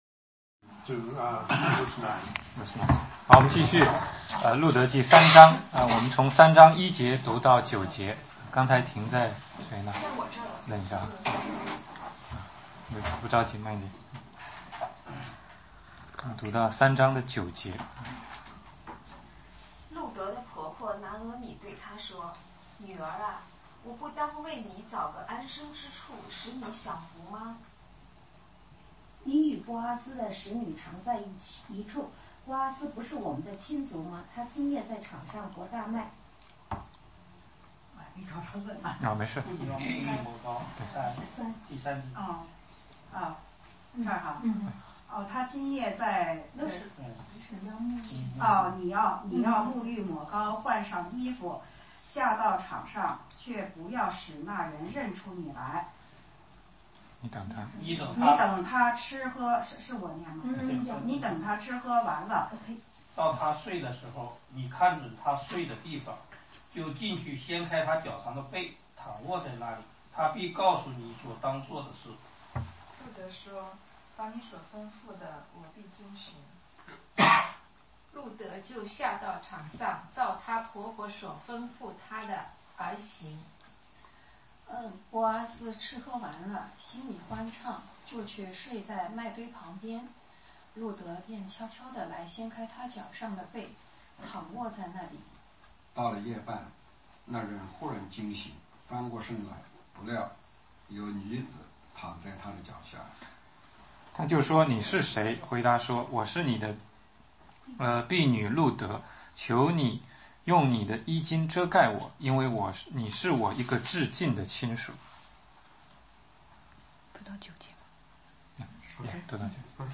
16街讲道录音 - 路德记3,4